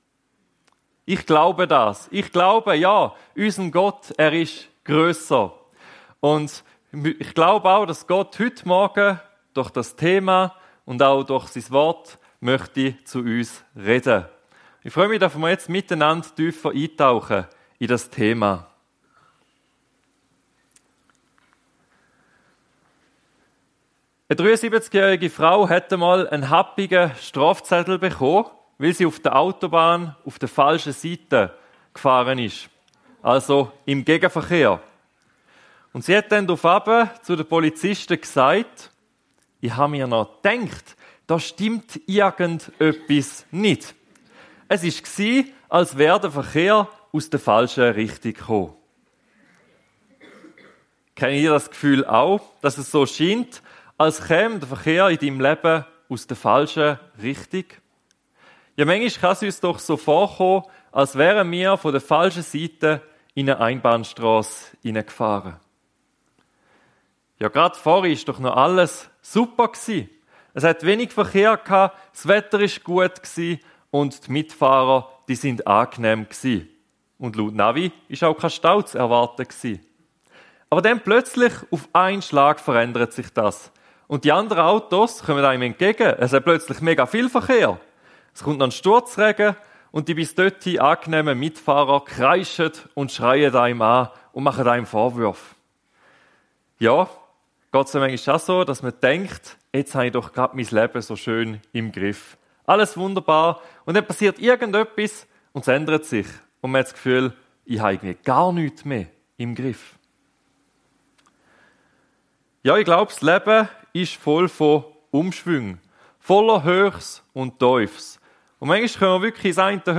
Thema: Gott ist grösser als meine Hochs und Tiefs Predigt